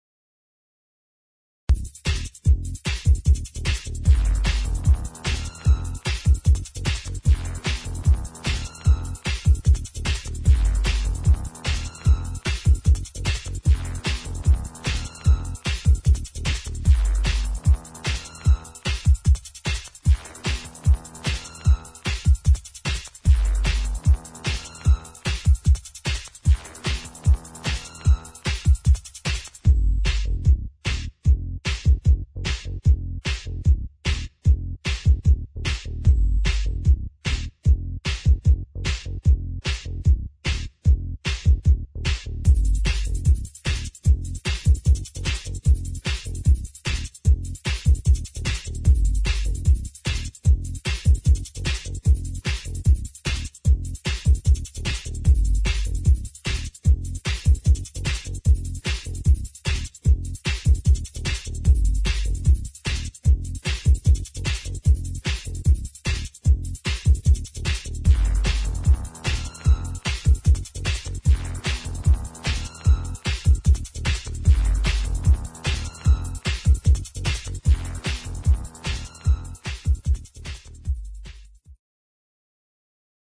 [ GHETTO | ELECTRO ]
エレクトロでファンキーなゲットー・テック・ベース！